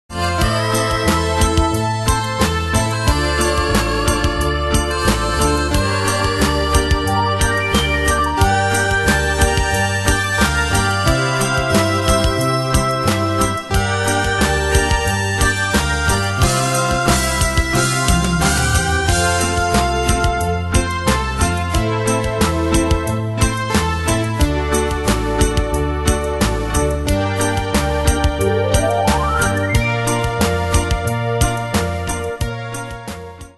Style: PopAnglo Ane/Year: 1974 Tempo: 90 Durée/Time: 3.36
Danse/Dance: Reggae Cat Id.
Pro Backing Tracks